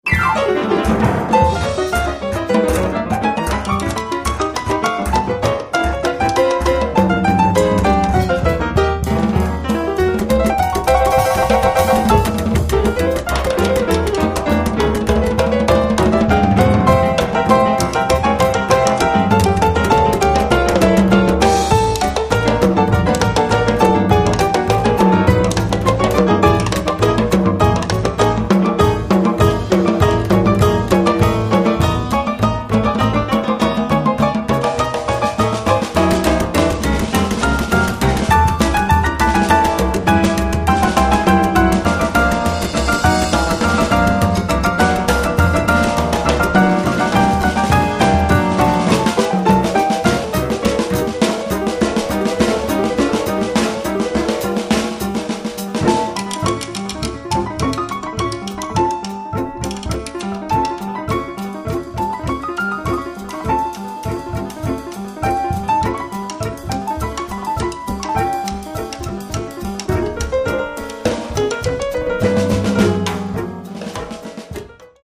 piano
batteria
contrabbasso